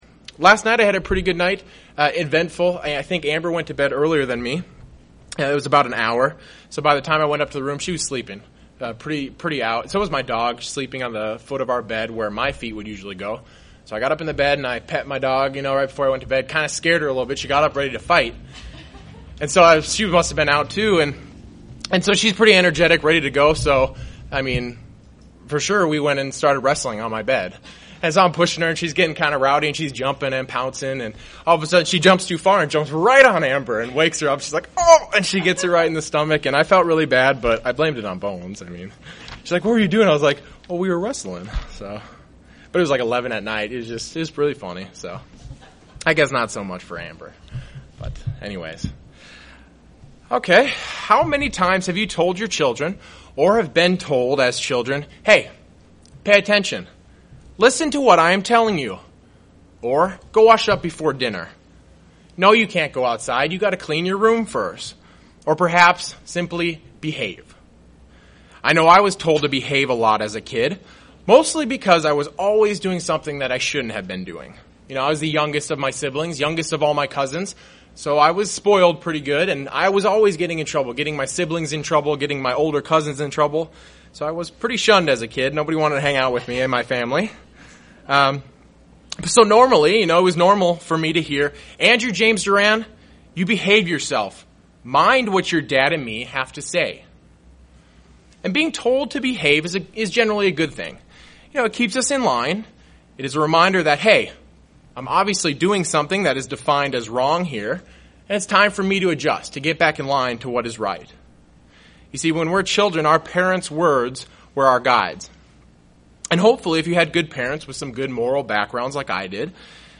In this split sermon, the speaker looks into three instructions on how a Christian should behave.